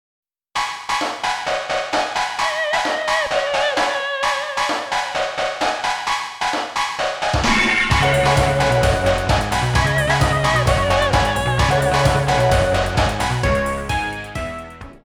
※一部の楽曲に収録の都合によりノイズが入る箇所があります。